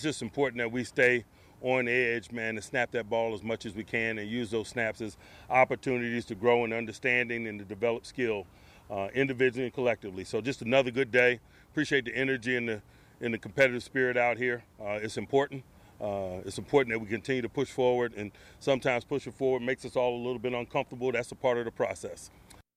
Tomlin also said yesterday’s practice was a good one and that the players need to remain focus on being consistent.